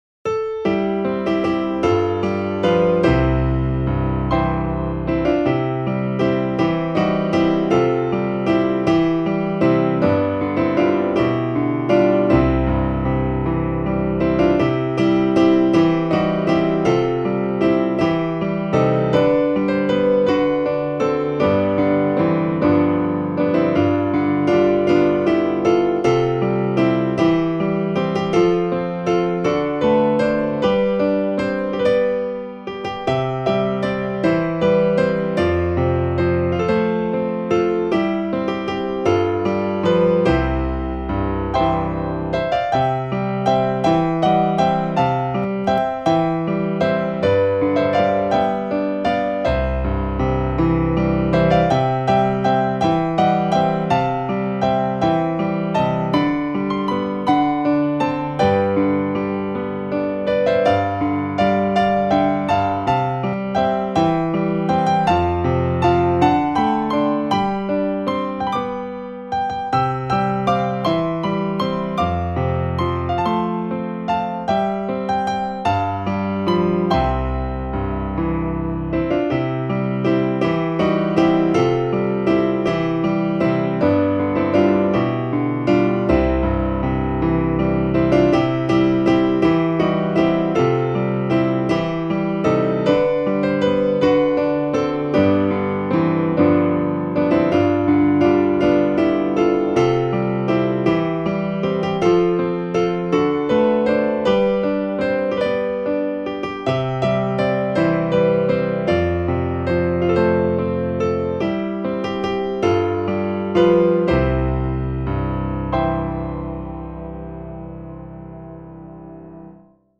This entry was posted on Sunday, December 18th, 2016 at 5:55 pm and is filed under hymns.